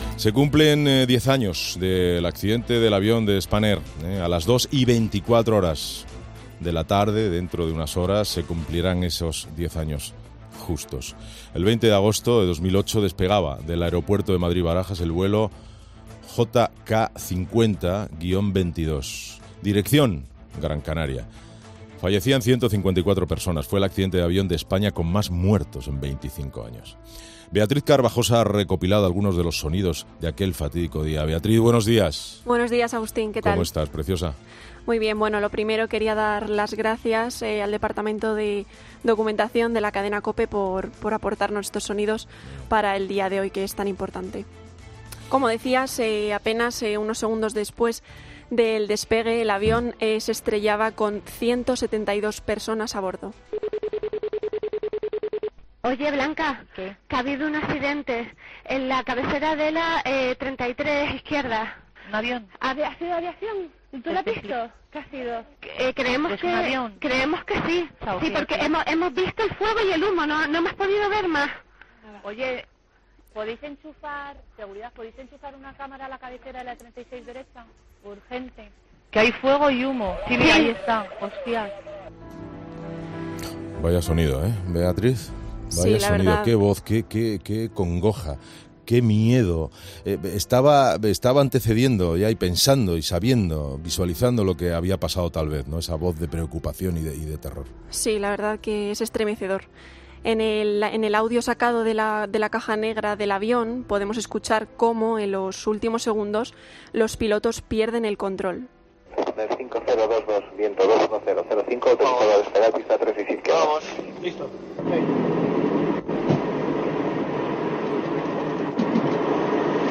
En COPE hemos recopilado los sonidos de esa jornada, con las conversaciones del puente de control del aeropuerto cuando se enteran del accidente, las últimas conversaciones de los pilotos antes de estrellarse y las declaraciones de los familiares y de los pocos supervivientes del accidente.